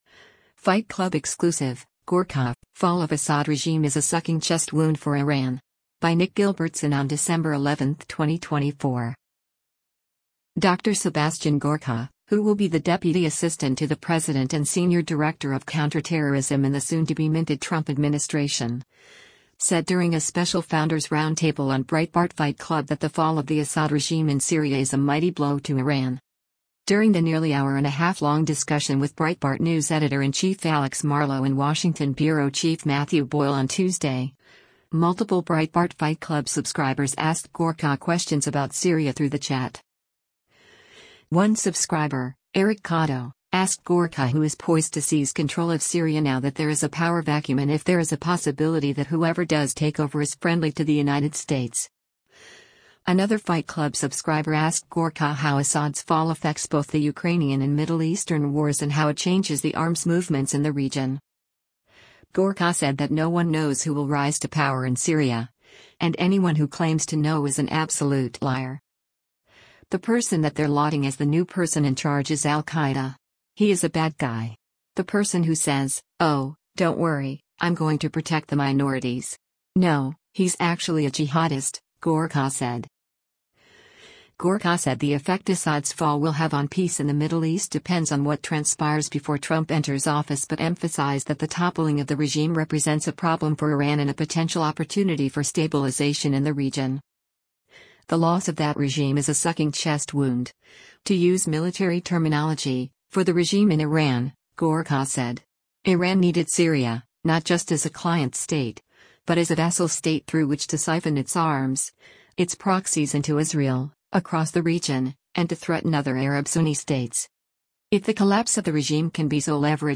Dr. Sebastian Gorka, who will be the deputy assistant to the president and senior director of counterterrorism in the soon-to-be-minted Trump administration, said during a special Founders Roundtable on Breitbart Fight Club that the fall of the Assad regime in Syria is a mighty blow to Iran.
multiple Breitbart Fight Club subscribers asked Gorka questions about Syria through the chat.